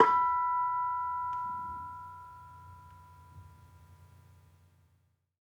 Bonang-B4-f.wav